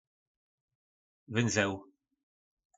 Slovník nářečí Po našimu
Uzel - Vynzeł